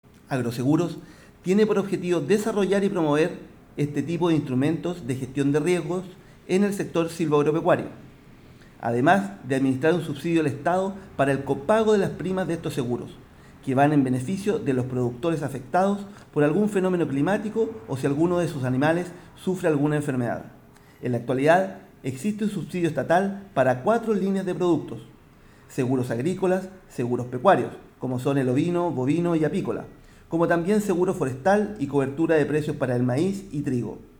El Seremi de Agricultura, Francisco Lagos, detalló que “el objetivo de los Seguros para el Agro es proteger a los agricultores de las pérdidas económicas que provocan los daños ocasionados por los eventos climáticos, eventos de la naturaleza y enfermedades, permitiéndoles recuperar el capital de trabajo invertido y de esta forma mejorar su estabilidad financiera permitiendo la continuidad del agricultor y su grupo familiar en la actividad productiva”, agregó Lagos.
Agroseguros-Seremi-Francisco-Lagos-02.mp3